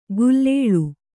♪ gullēḷu